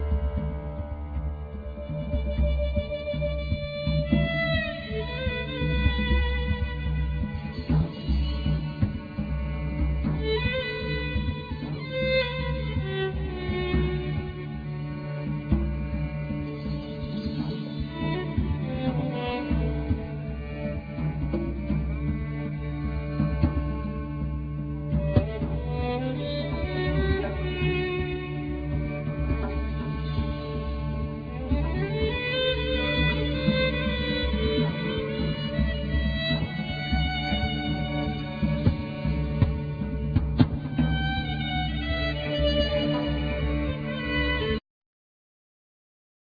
Flute,Piano,Percussion
Violin
Acoustic guitar,Dombra,Bayan